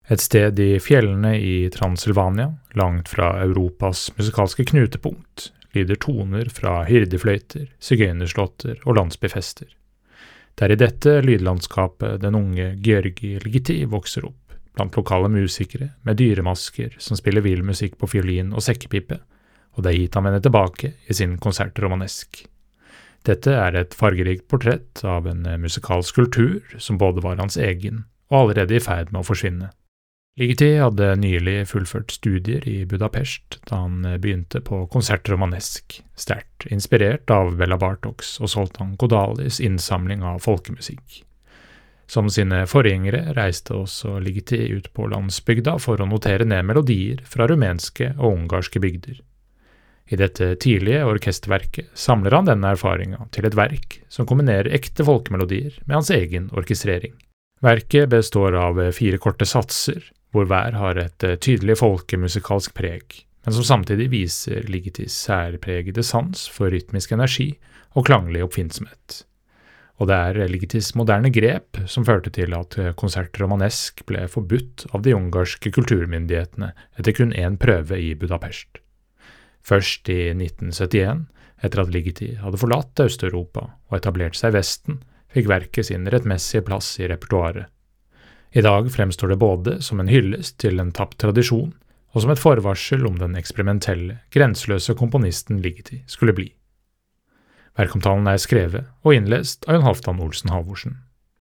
VERKOMTALE-Gyorgi-Ligetis-Concert-Romanesc.mp3